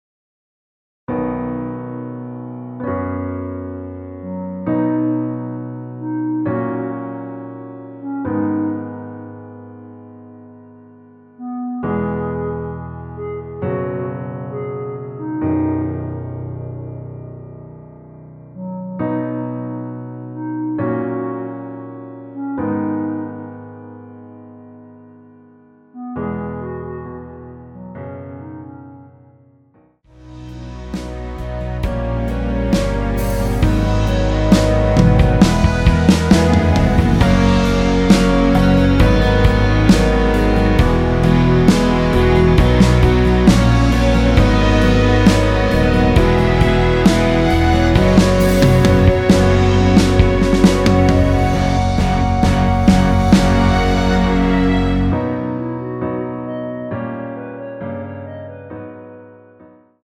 전주없이 시작하는 곡이라 1마디 전주 만들어 놓았습니다.(미리듣기 참조)
원키에서(-2)내린 멜로디 포함된 MR입니다.(미리듣기 확인)
앞부분30초, 뒷부분30초씩 편집해서 올려 드리고 있습니다.
중간에 음이 끈어지고 다시 나오는 이유는